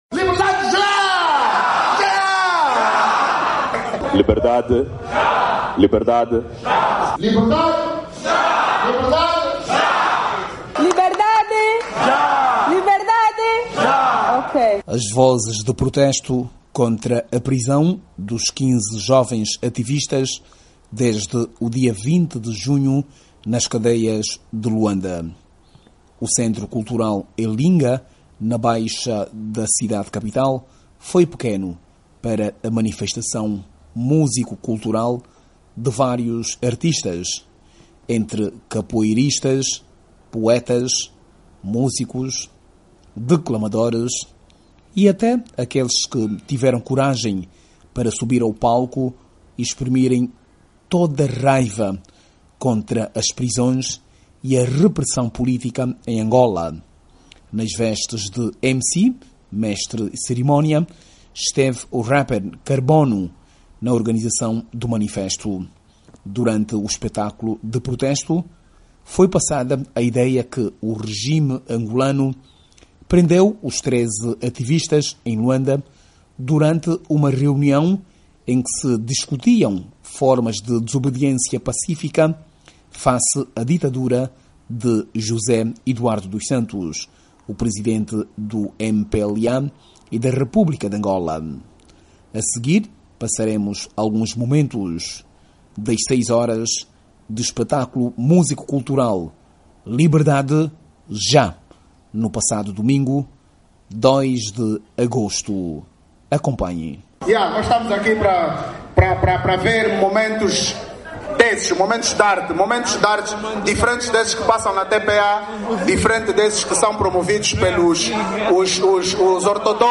“Liberdade Já!” Foi o que mais se ouviu no domingo 02 de Agosto no Centro Cultural Elinga Teatro na baixa de Luanda, num manifesto músico cultural organizado por vários jovens da sociedade civil para exigir a liberdade dos 15 activistas presos desde o dia 20 de Junho em algumas cadeias de...